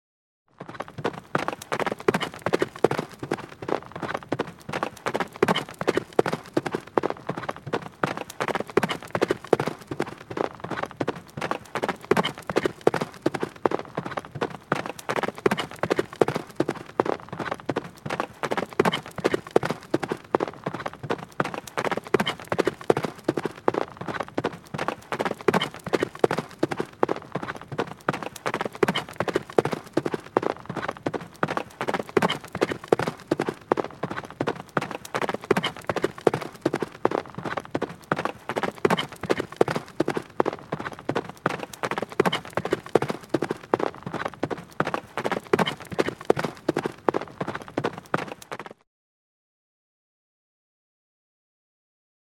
Гул копыт бегущей или скачущей лошади